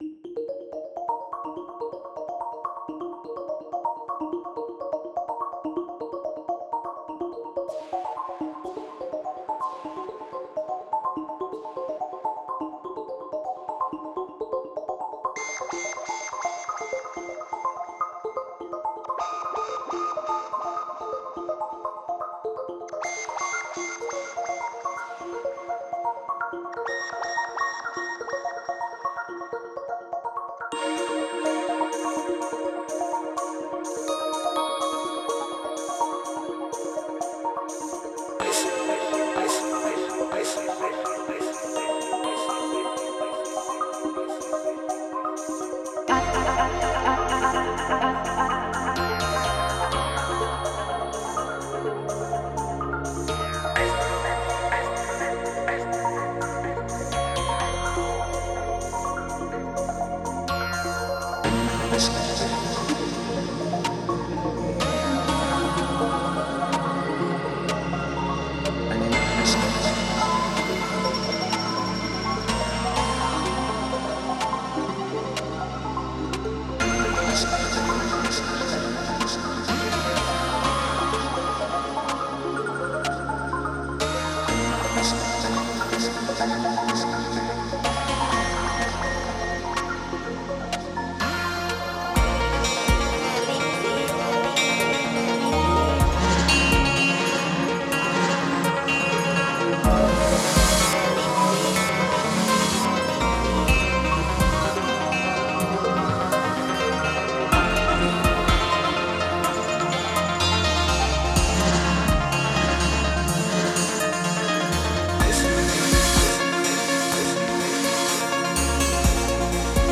Genre: IDM, Electronic.